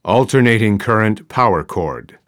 AC_power_cord.wav